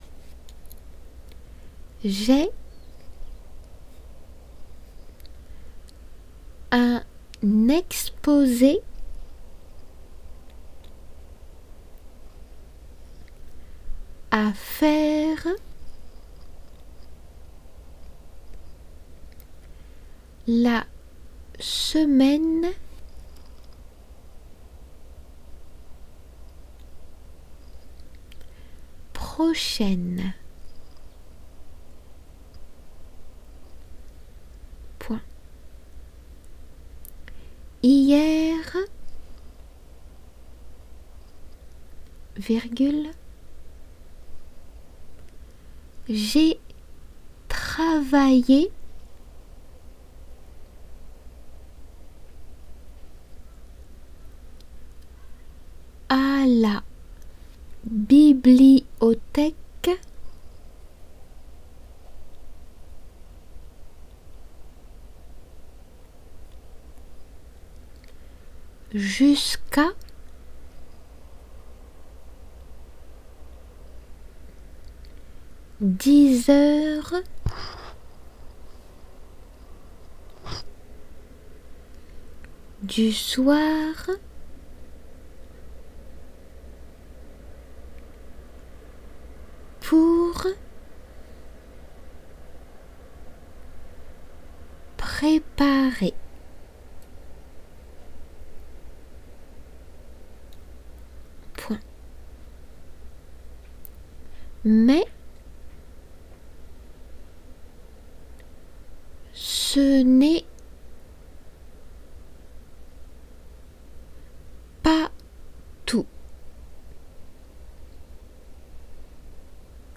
デイクテの速さで
仏検デイクテ0521m-dictee.m4a